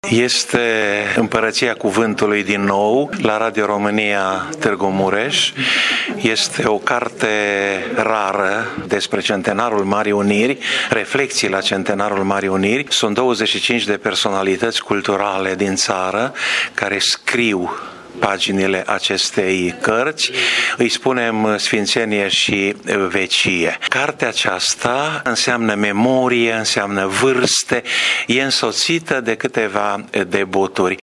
Lansarea cărții a fost organizată de Radio Tîrgu-Mureș și a avut loc în studioul 3 al instituției noastre.